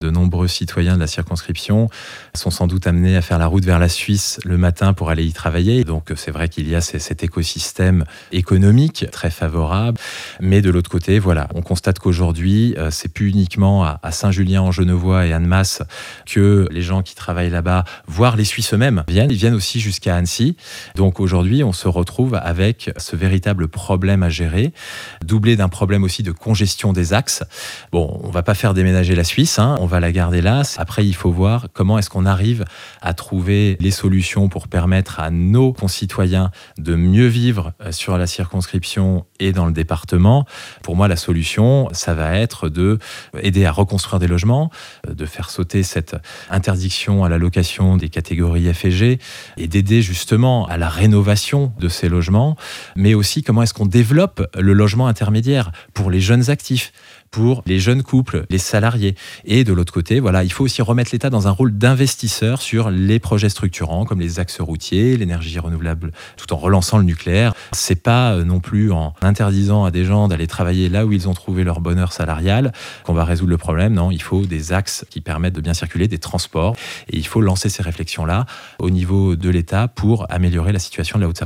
Voic les interviews des 8 candidats de cette 2eme circonscription de Haute-Savoie (par ordre du tirage officiel de la Préfecture) :